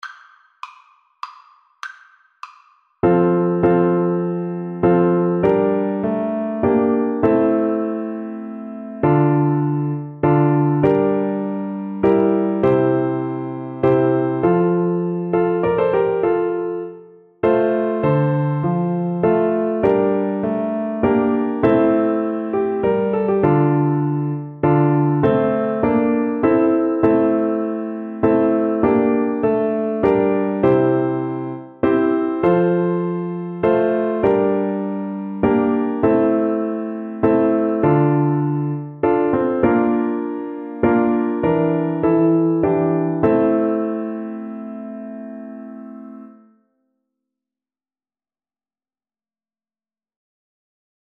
Traditional Trad. Hitragut (Jewish Trad) Soprano (Descant) Recorder version
F major (Sounding Pitch) (View more F major Music for Recorder )
Con moto
3/4 (View more 3/4 Music)
Traditional (View more Traditional Recorder Music)
world (View more world Recorder Music)